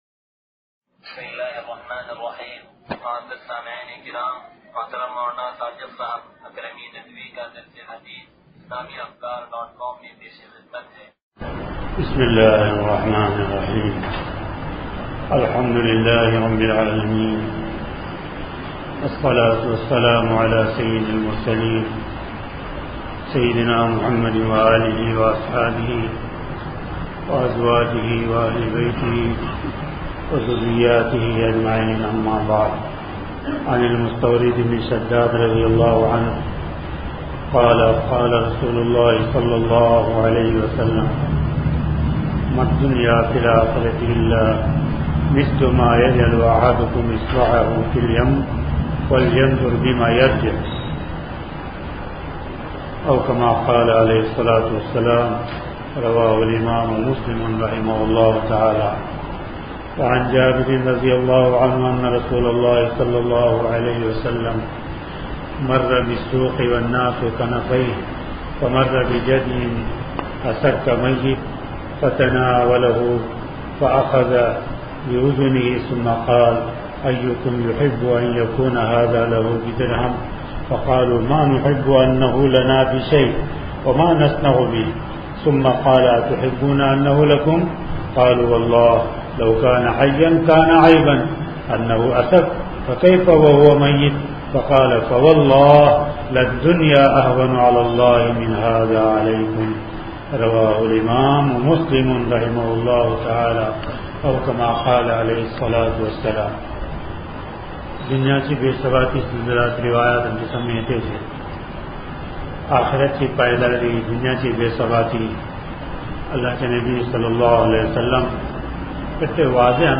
درس حدیث نمبر 0494